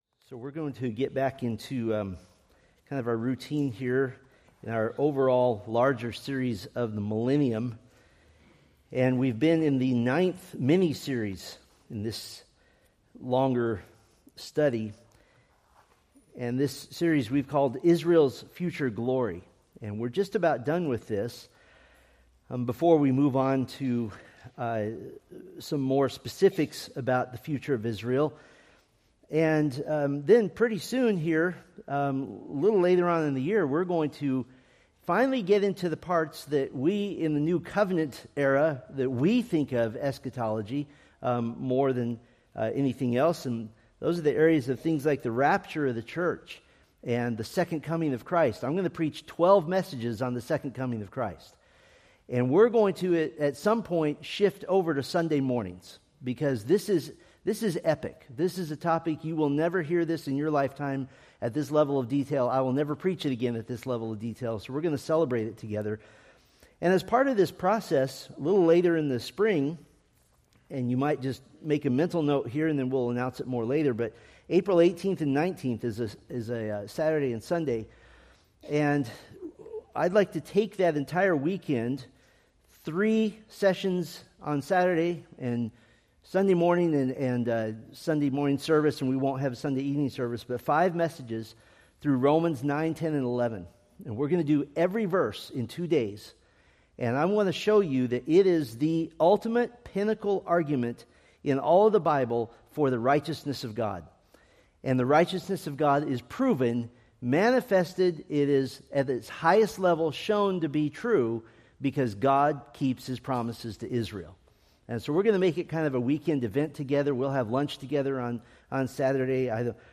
From the Millennium: Israel's Future Glory sermon series.